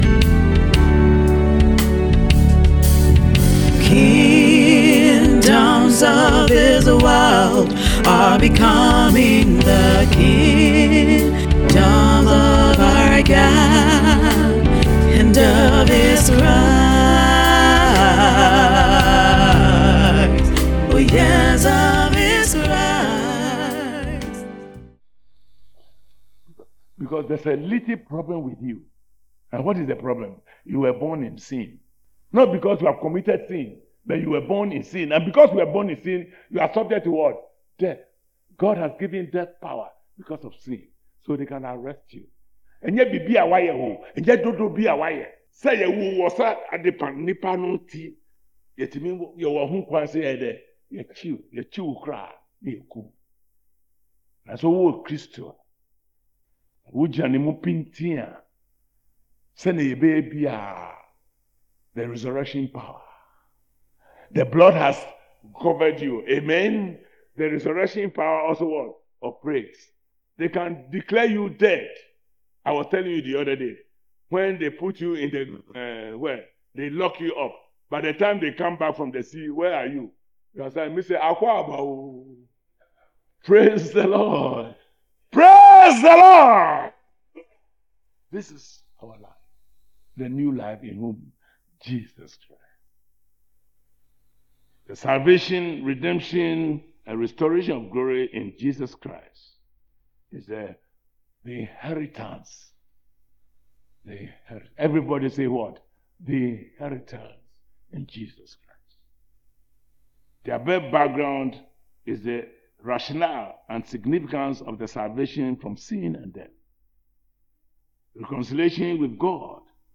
SERMON TITLE: The Victory Life: Faith, Redemption, and the Resurrection Power.